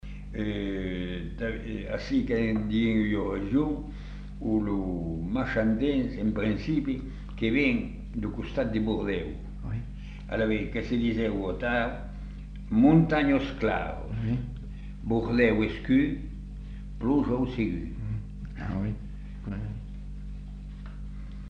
Lieu : Masseube
Effectif : 1
Type de voix : voix d'homme
Production du son : récité
Classification : proverbe-dicton